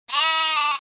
balido
Sonido FX 5 de 42
balido.mp3